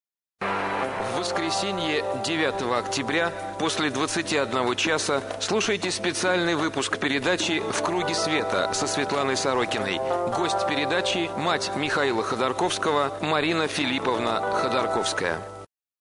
программа Светланы Сорокиной на радио «Эхо Москвы»
Анонс: